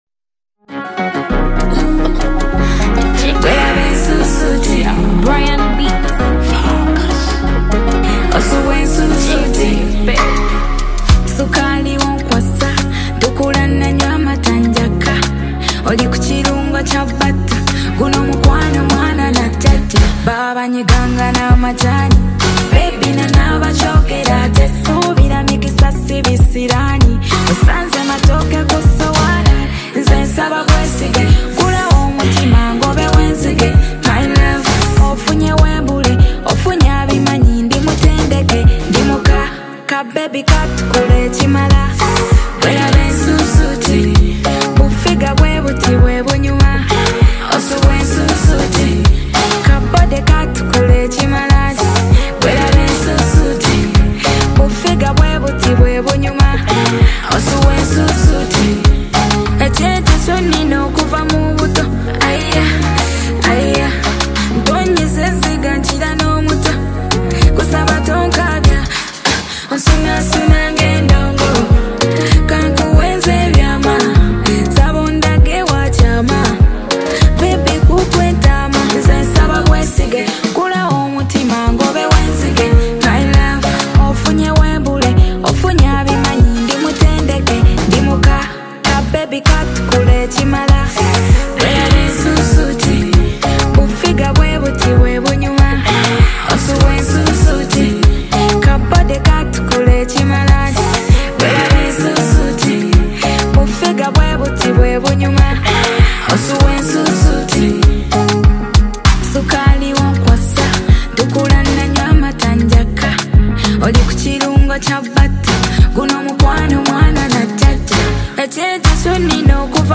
Afro Beat